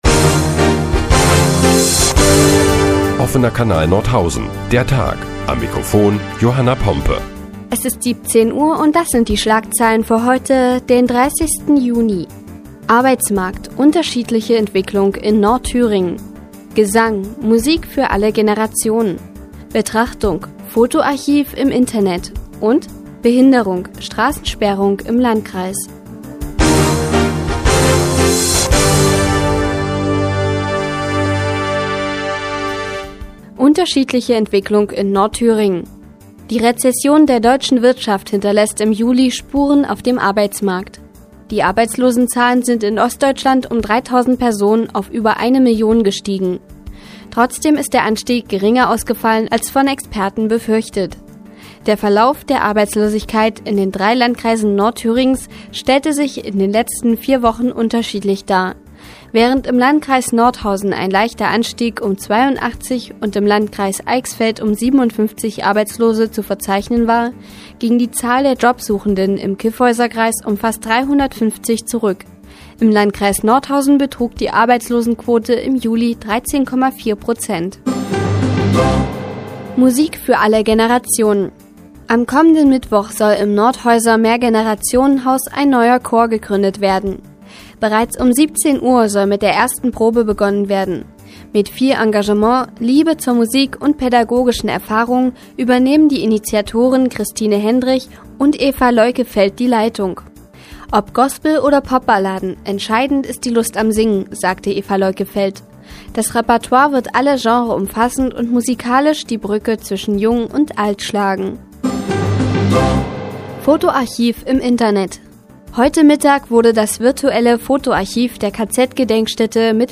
Die tägliche Nachrichtensendung des OKN ist nun auch in der nnz zu hören. Heute geht es unter anderem um Musik für alle Generationen und Straßensperrungen im Landkreis.